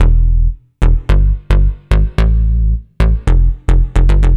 AM_OB-Bass_110-E.wav